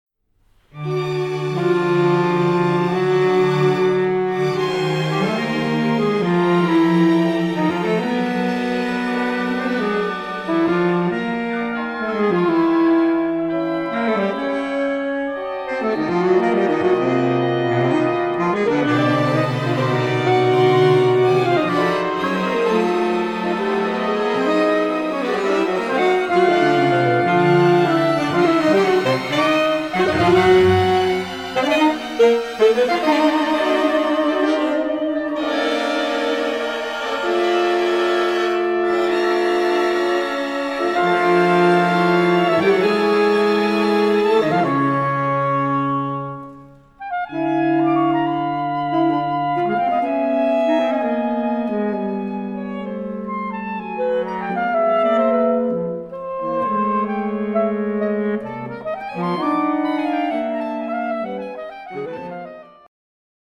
percussion - cordes